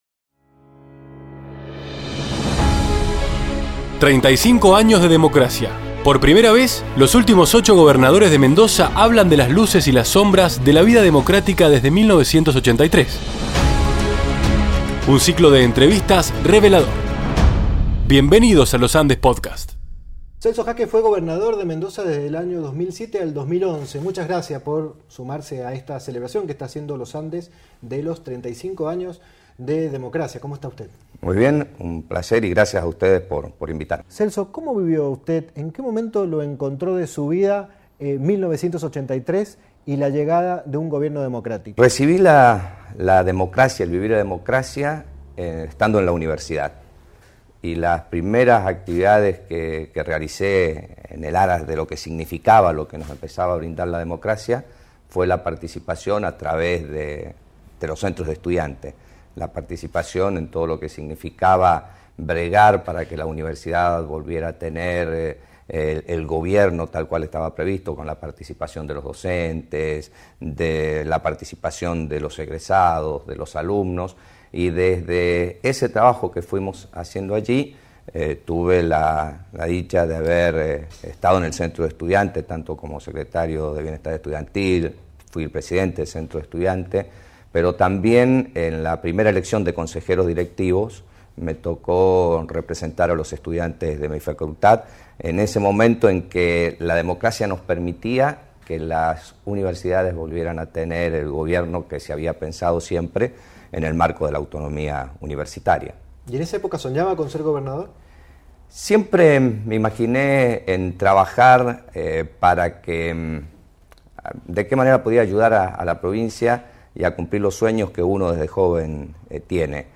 35 AÑOS DE DEMOCRACIA: entrevista a Celso Jaque